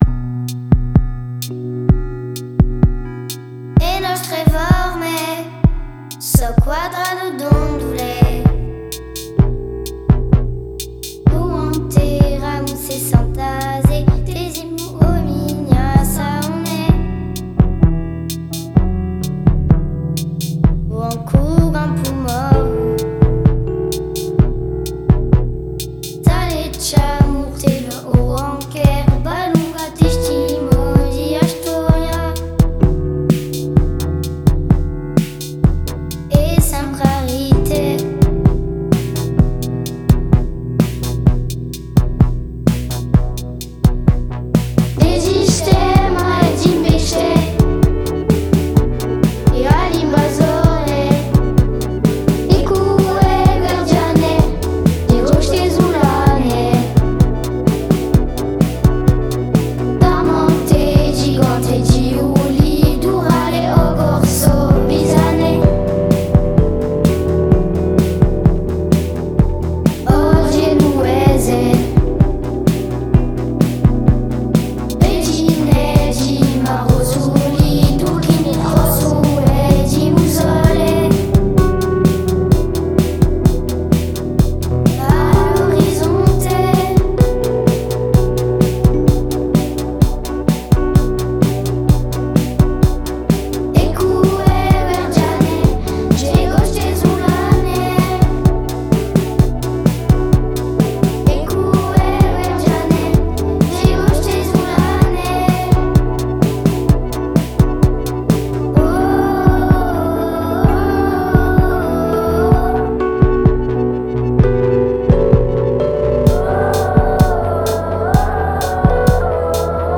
Version remasterisée